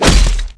拳头击中zth070521.wav
通用动作/01人物/03武术动作类/拳头击中zth070521.wav
• 声道 單聲道 (1ch)